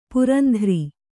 ♪ purandhri